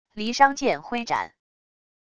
离殇剑挥斩wav音频